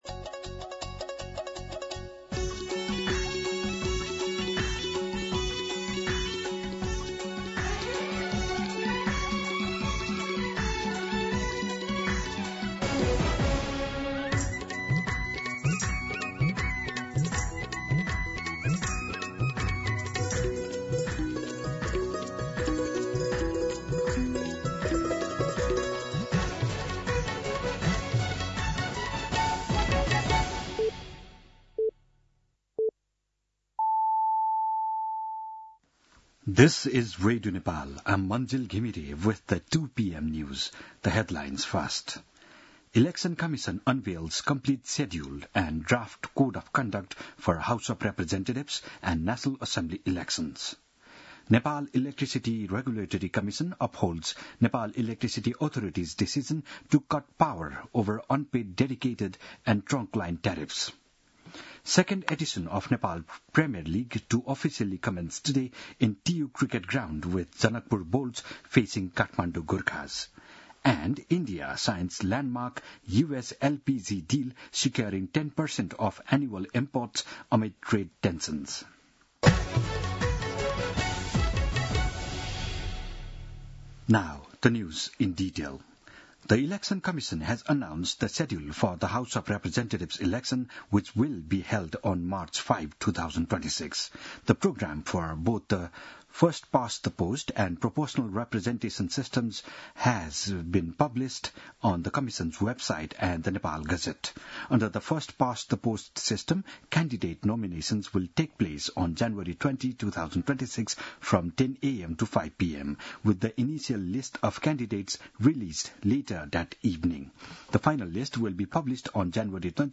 दिउँसो २ बजेको अङ्ग्रेजी समाचार : १ मंसिर , २०८२